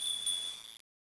xmas_deploy.wav